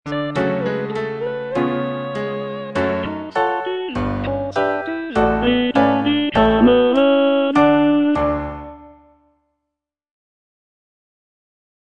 G. BIZET - CHOIRS FROM "CARMEN" Répondez, camarade (tenor II) (Voice with metronome) Ads stop: auto-stop